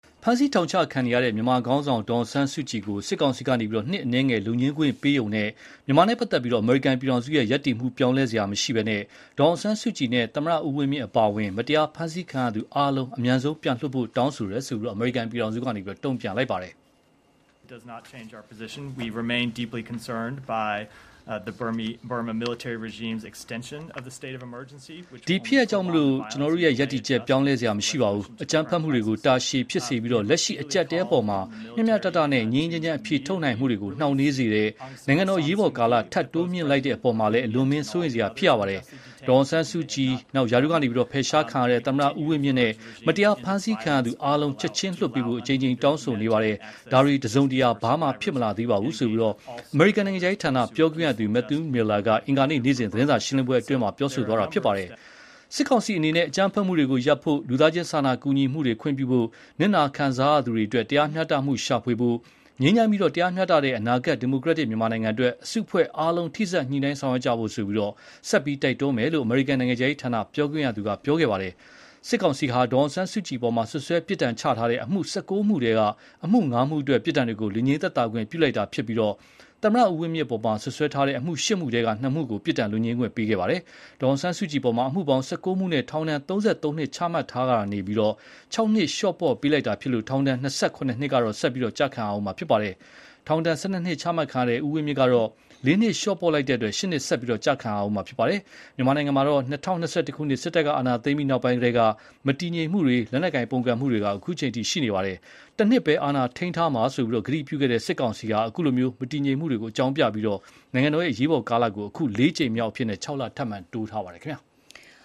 US ှState Department Spokesperson
အမေရိကန်နိုင်ငံခြားရေးဌာန ပြောခွင့်ရသူ Matthew Miller က အင်္ဂါနေ့ နေ့စဉ်သတင်းစာရှင်းလင်းပွဲအတွင်း ပြောဆိုသွားတာပါ။ စစ်ကောင်စီအနေနဲ့ အကြမ်းဖက်မှုတွေကို ရပ်ဖို့၊ လူသားချင်းစာနာကူညီမှုတွေ ခွင့်ပြုဖို့၊ နစ်နာခံစားရသူတွေအတွက် တရားမျှတမှုရှာဖွေဖို့၊ ငြိမ်းချမ်းပြီး တရားမျှတတဲ့ အနာဂတ် ဒီမိုကရက်တစ် မြန်မာနိုင်ငံအတွက် အစုအဖွဲ့အားလုံး ထိစပ်ညှိုနှိုင်းဆောင်ရွက်ဖို့ ဆက်ပြီးတိုက်တွန်းသွားမယ်လို့လည်း အမေရိကန်နိုင်ငံခြားရေးဌာန ပြောခွင့်ရသူက ပြောခဲ့ပါတယ်။ စစ်ကောင်စီဟာ ဒေါ်အောင်ဆန်းစုကြည် အပေါ် စွပ်စွဲ ပြစ်ဒဏ်ချမှတ်ထားတဲ့ အမှု ၁၉ မှုထဲက အမှု ၅ မှုအတွက် ပြစ်ဒဏ်တွေကို လွတ်ငြိမ်း သက်သာခွင့် ပြုလိုက်တာ ဖြစ်ပြီး သမ္မတ ဦးဝင်းမြင့် အပေါ် စွပ်စွဲထားတဲ့ အမှု ၈ မှုထဲက ၂ မှုကို ပြစ်ဒဏ် လွတ်ငြိမ်းခွင့် ပြုလိုက်တာ ဖြစ်ပါတယ်။ ဒေါ်အောင်ဆန်းစုကြည်အပေါ် အမှုပေါင်း ၁၉ မှုနဲ့ ထောင်ဒဏ် ၃၃ နှစ် ချမှတ်ထားတာကနေ ၆ နှစ် လျှော့ပေါ့ပေးလိုက်တာ ဖြစ်လို့ ထောင်ဒဏ် ၂၇ နှစ် ကတော့ ဆက်လက် ကျခံရဦးမှာ ဖြစ်ပါတယ်။ ထောင်ဒဏ် ၁၂ နှစ်ချမှတ်ခံထားရတဲ့ ဦးဝင်းမြင့်ကတော့ ၄ နှစ် လျှော့ပေါ့လိုက်တဲ့ အတွက် ၈ နှစ် ဆက်လက် ကျခံရဦးမှာ ဖြစ်ပါတယ်။ မြန်မာနိုင်ငံမှာ ၂၀၂၁ ခုနှစ် စစ်တပ်ကအာဏာသိမ်းပြီးနောက်ပိုင်းကတည်းက မတည်ငြိမ်မှုတွေ၊ လက်နက်ကိုင်ပုန်ကန်မှုတွေ အခုအချိန်အထိ ရှိနေပါတယ်။ တနှစ်ပဲ အာဏာထိမ်းထားမှာလို့ ဂတိပြုခဲ့တဲ့ စစ်ကောင်စီဟာ၊ အခုလို မတည်ငြိမ်မှုတွေကို အကြောင်းပြပြီး နိုင်ငံတော်အရေးပေါ်ကာလကို အခု လေးကြိမ်မြောက်အဖြစ် ၆ လ ထပ်မံ တိုးမြှင့်ထားပါတယ်။